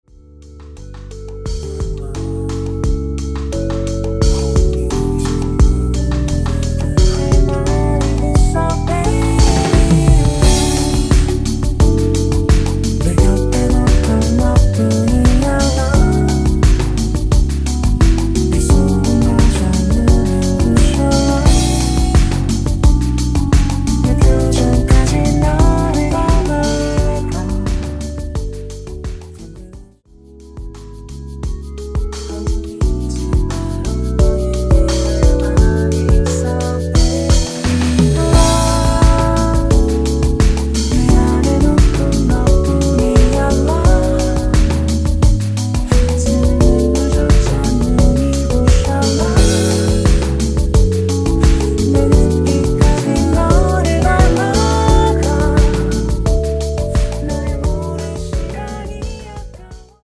엔딩이 페이드 아웃이라 노래 끝나고 바로 끝나게 엔딩을 만들어 놓았습니다.(원키 멜로디 MR 미리듣기 확인)
원키에서(-2)내린 멜로디와 코러스 포함된 MR입니다.(미리듣기 확인)
앞부분30초, 뒷부분30초씩 편집해서 올려 드리고 있습니다.
중간에 음이 끈어지고 다시 나오는 이유는